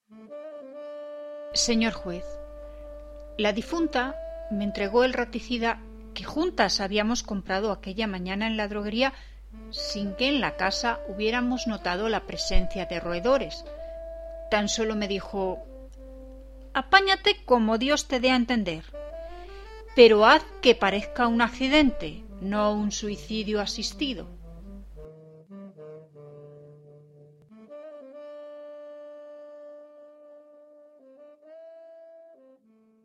Maravilloso audio relato 🙂 feliz domingo cariño !!!